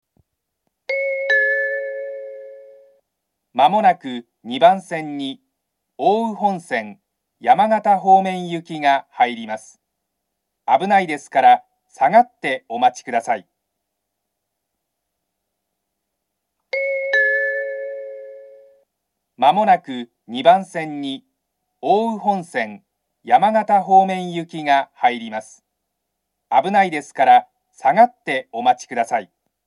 ２番線上り接近放送